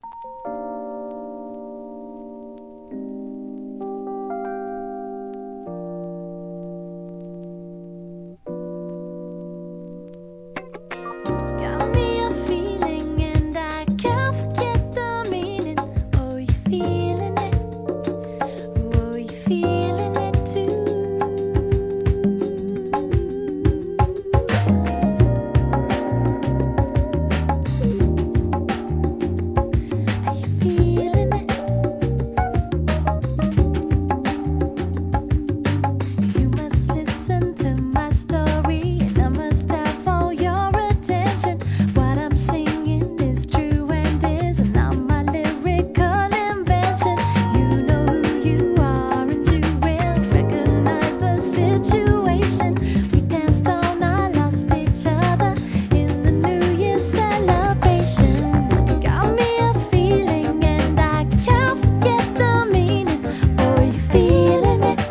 HIP HOP/R&B
1993年、JAZZYなGROOVEでキュートなボーカルの人気UK SOUL!!